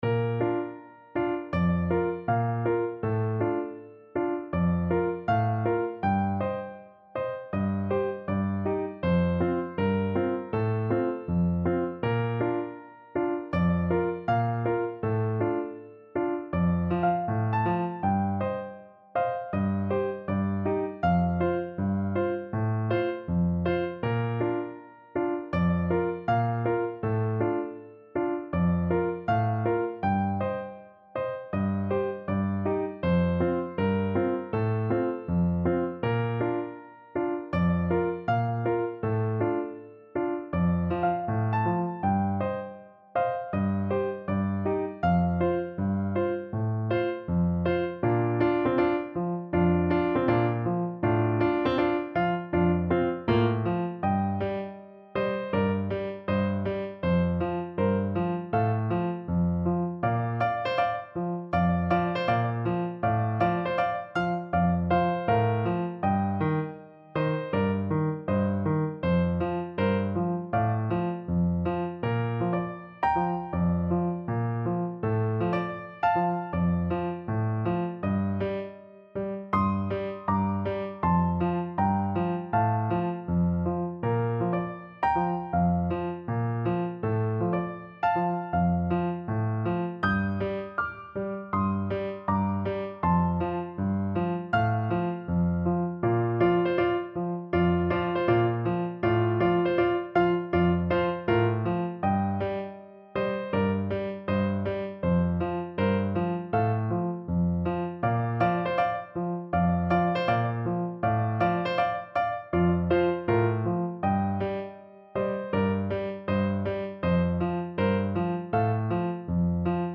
4/4 (View more 4/4 Music)
Piano  (View more Intermediate Piano Music)
Pop (View more Pop Piano Music)